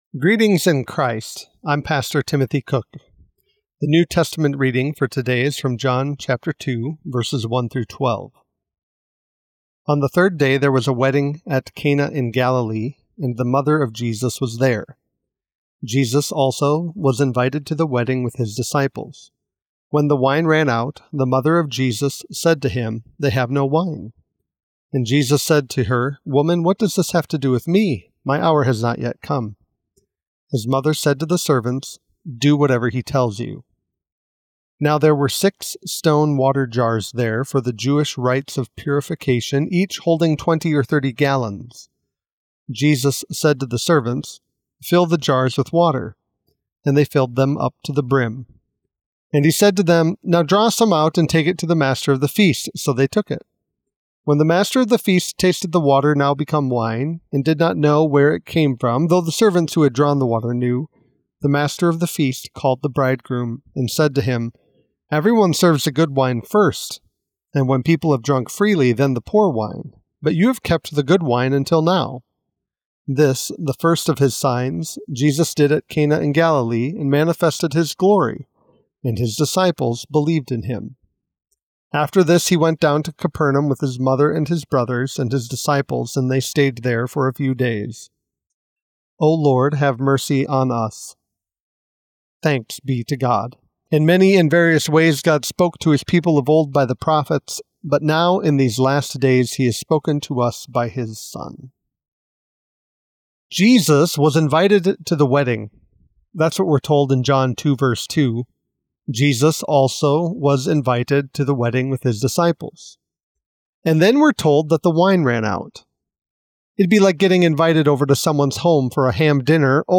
Morning Prayer Sermonette: John 2:1-12
Hear a guest pastor give a short sermonette based on the day’s Daily Lectionary New Testament text during Morning and Evening Prayer.